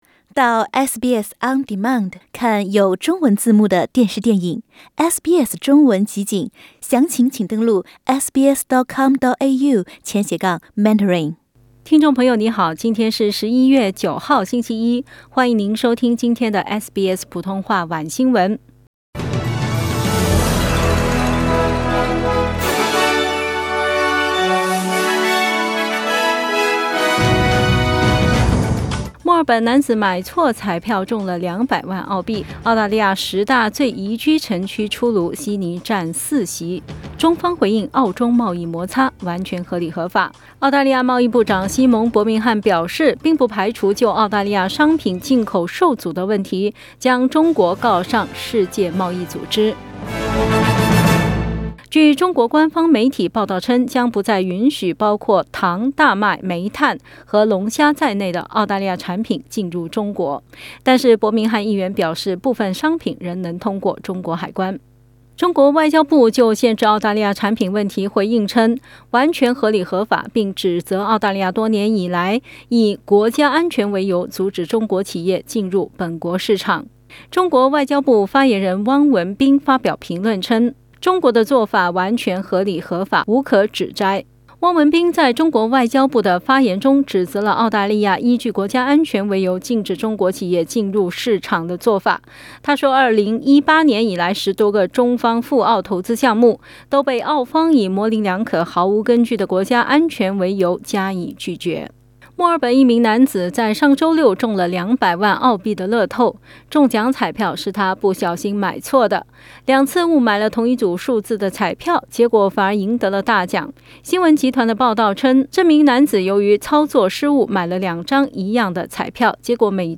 SBS晚新聞（11月9日）